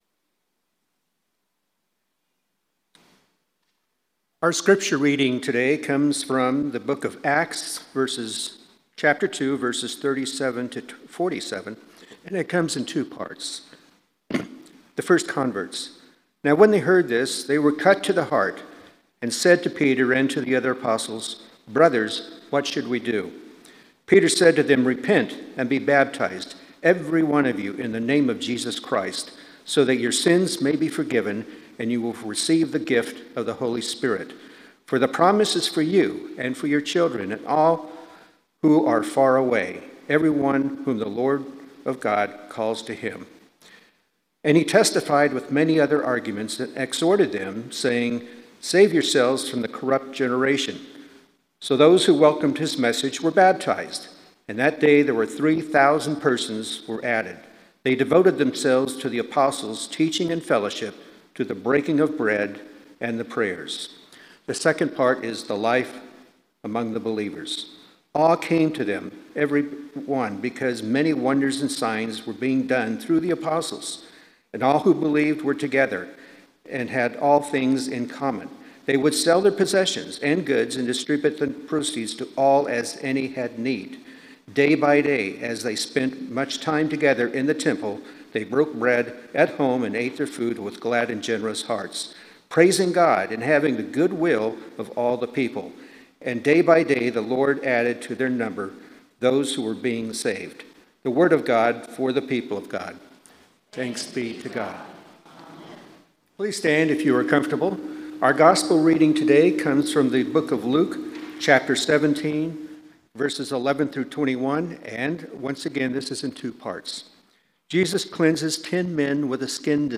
Sermon – Methodist Church Riverside